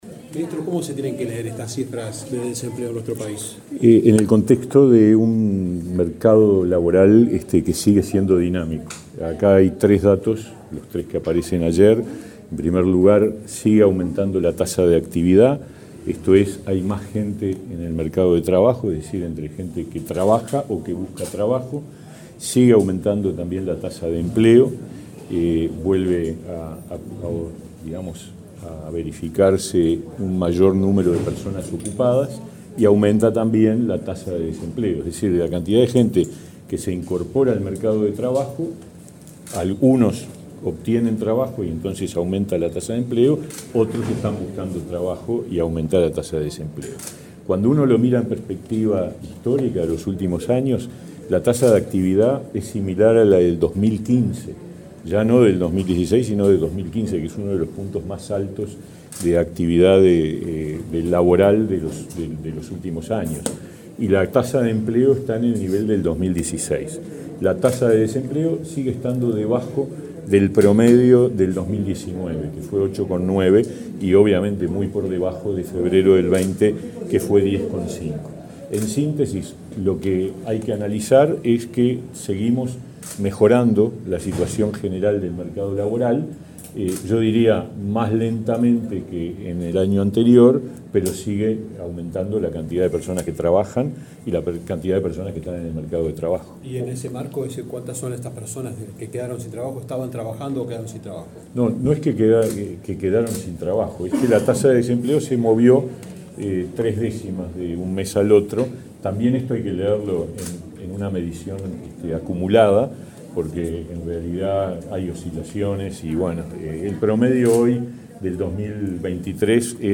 Declaraciones del ministro de Trabajo, Pablo Mieres